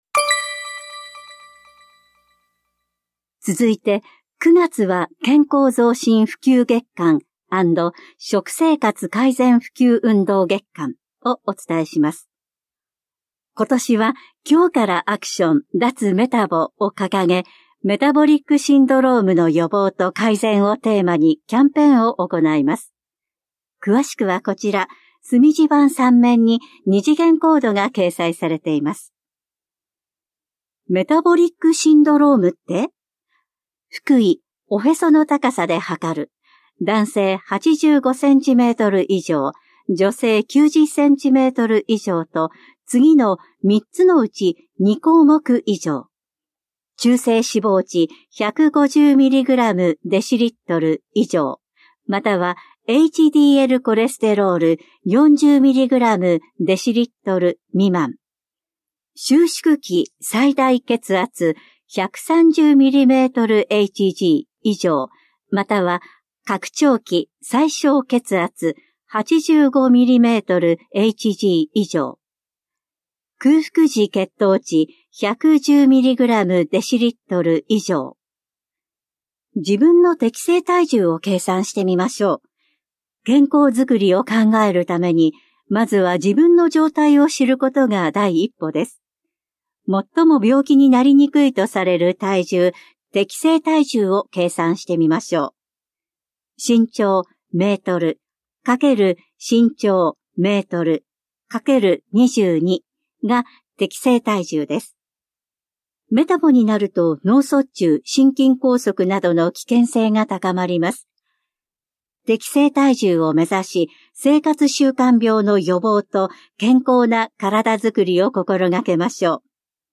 広報「たいとう」令和6年9月5日号の音声読み上げデータです。